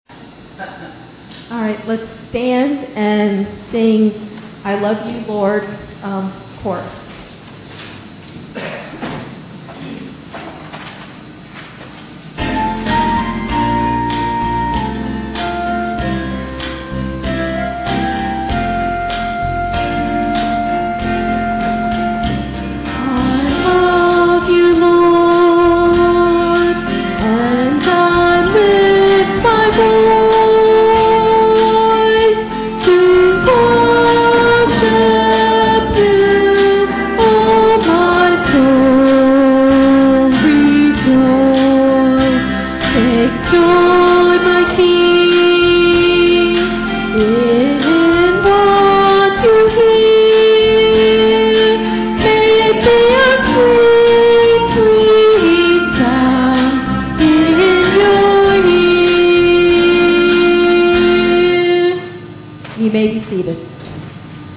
Bethel Church Service
Hymn of Preparation